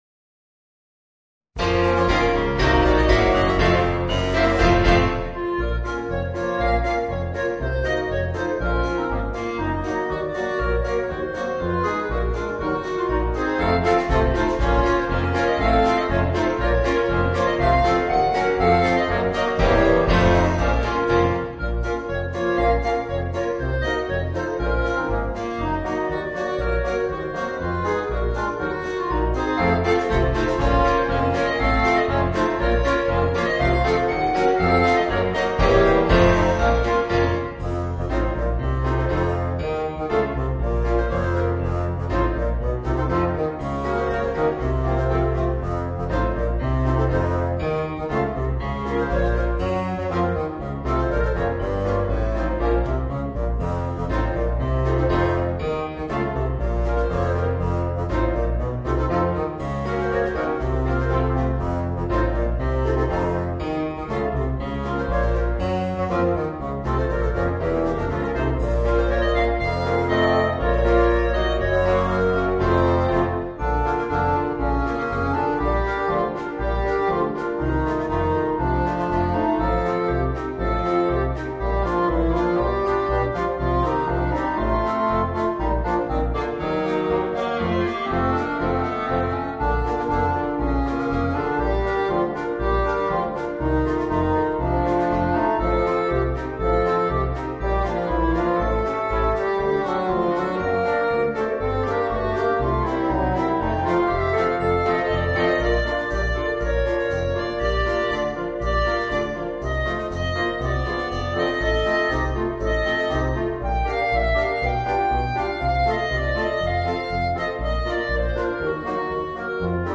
Voicing: Woodwind Quintet and Rhythm Section